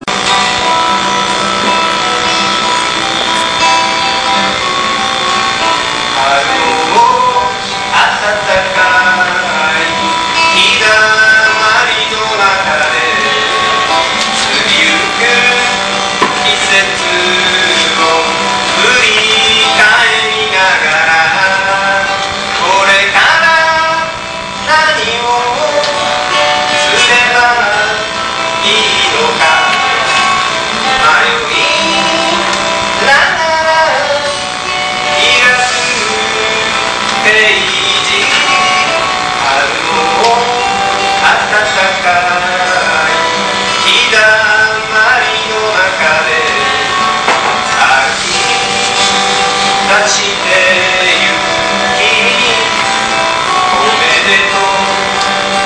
ギター ２
久々にライブをやりました。
音は悪いですが、
二番まであり、間奏で語りが入ります。
ビデオ録画をして、その音声をテープにダビングし、